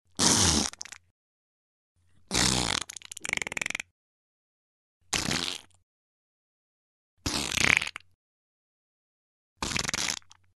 Звуки пука, пердежа
Человек пёрнул с подливкой